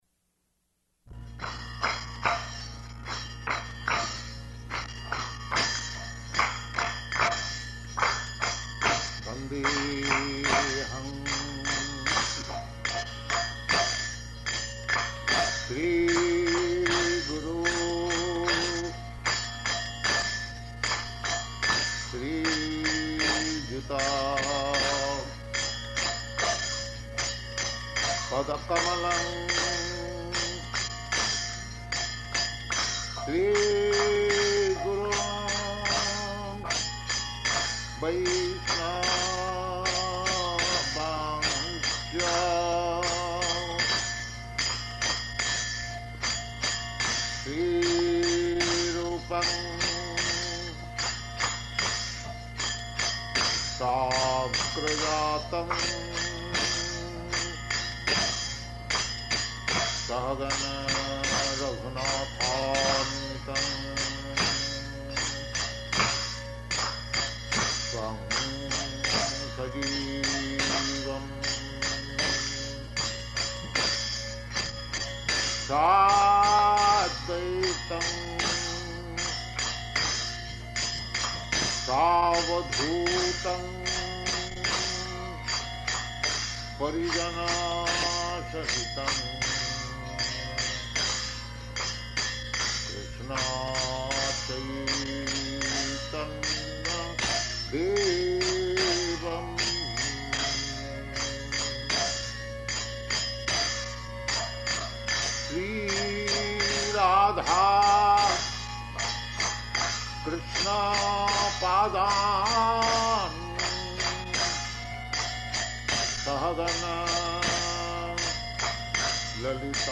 Location: San Francisco
Prabhupāda: [chants maṅgalācaraṇa prayers]